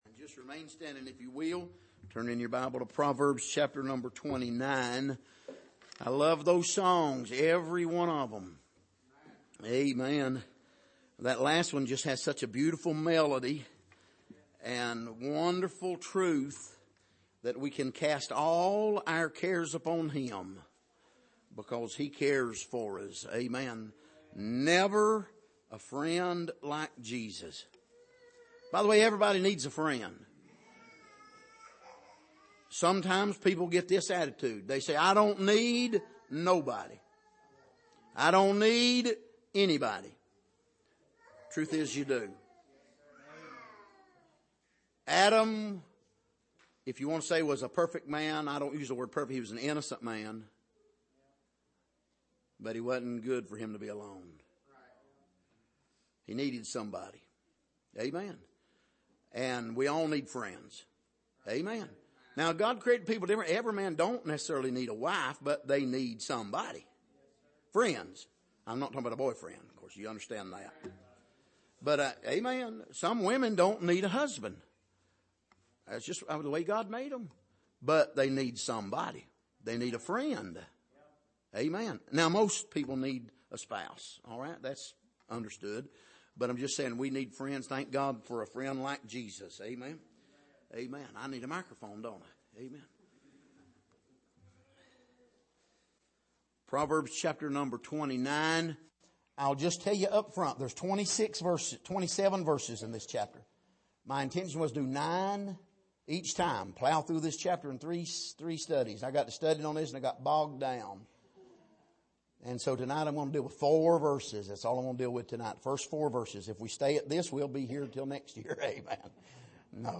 Passage: Proverbs 29:1-4 Service: Sunday Evening